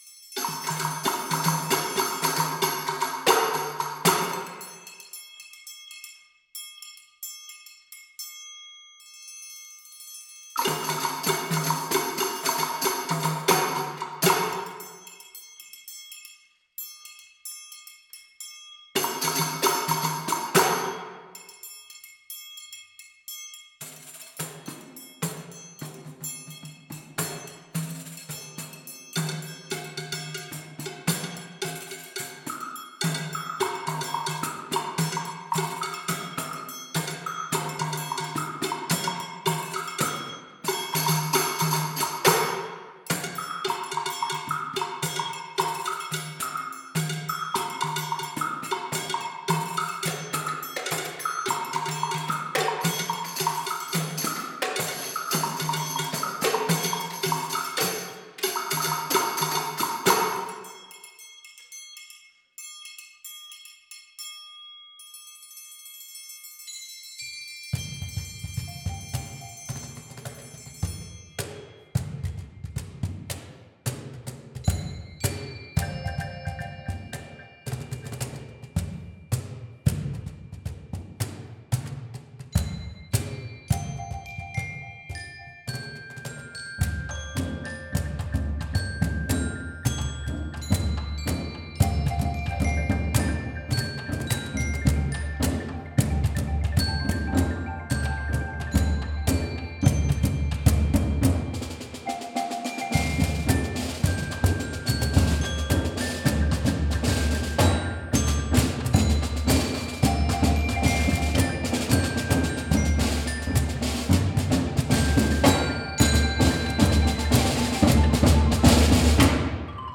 Genre: Percussion Ensemble
# of Players: 7
Player 1: Triangle, Glockenspiel
Player 2: Cowbell, Crotales (lower octave)
Player 3: High Woodblock, Xylophone (shared)
Player 4: Low Woodblock, Xylophone (shared)
Player 5: Tamborim, Piccolo Snare Drum
Player 6: Tambourine, Tan-Tan
Player 7: Pandeiro, Cajón